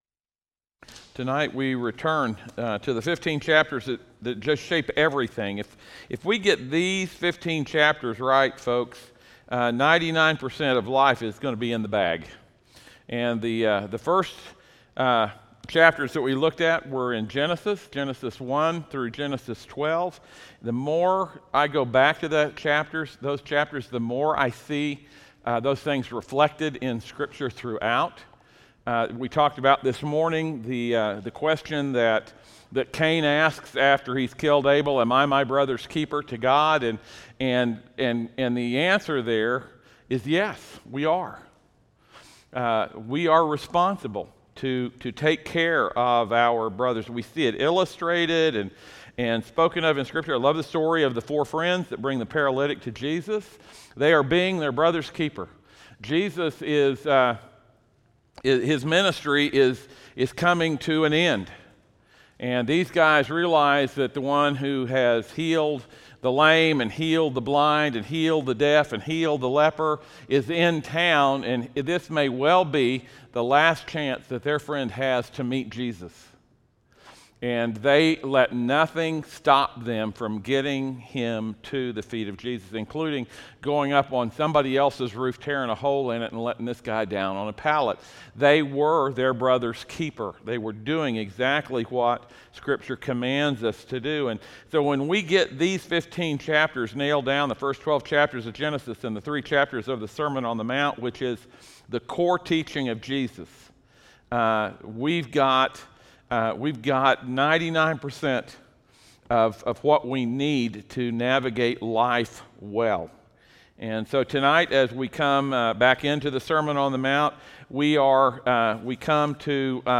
Passage: Matthew 5:9 Service Type: audio sermons